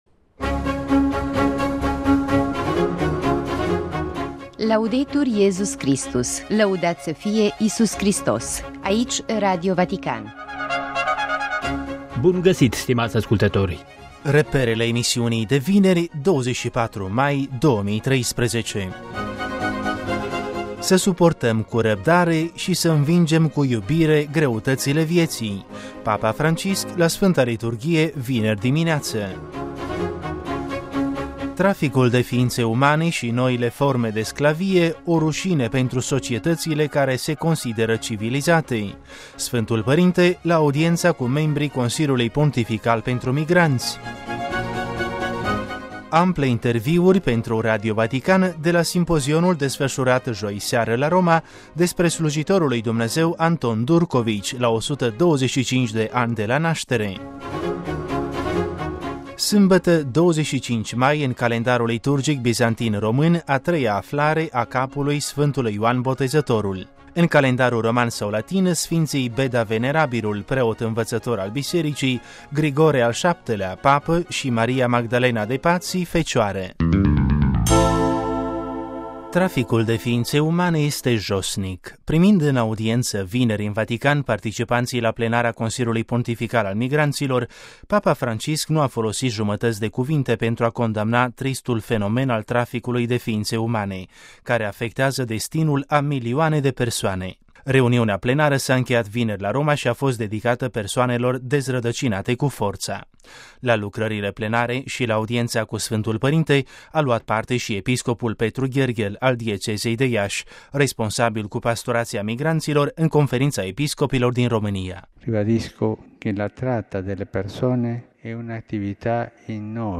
Traficul de fiinţe umane şi noile forme de sclavie, o ruşine pentru societăţile care se consideră civilizate: Sfântul Părinte, la audienţa cu membrii Consiliului Pontifical pentru Migranţi - Ample interviuri pentru Radio Vatican